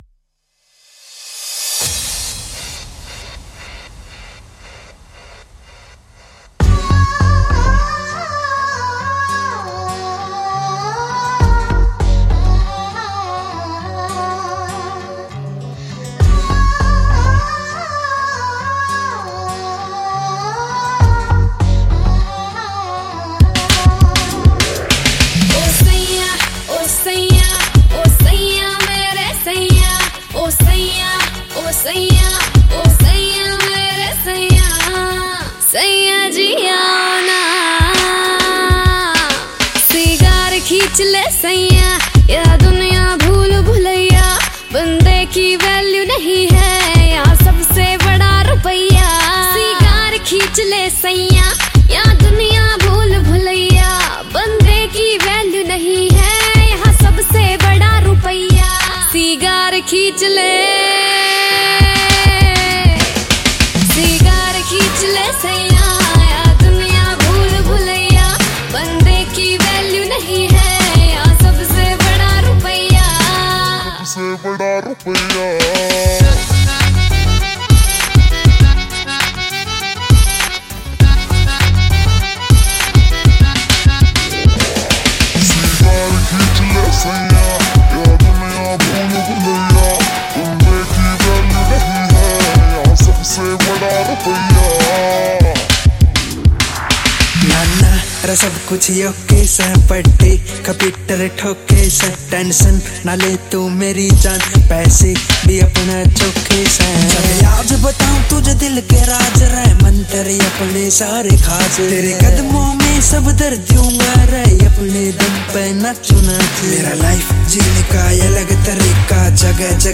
Haryanvi